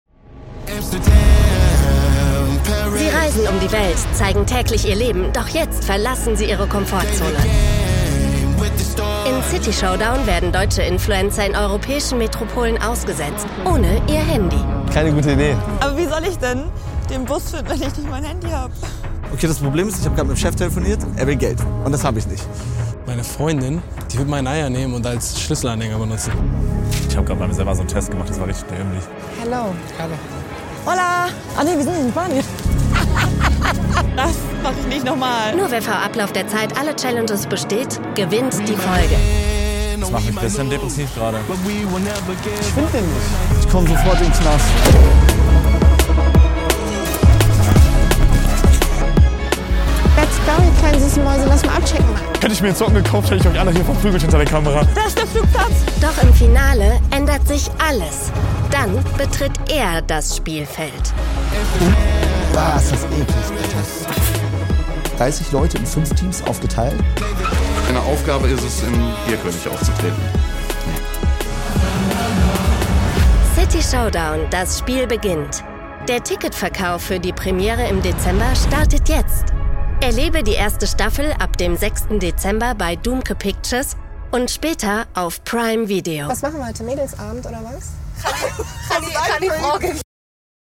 hell, fein, zart, sehr variabel
Mittel minus (25-45)
Eigene Sprecherkabine
Trailer I Reality Show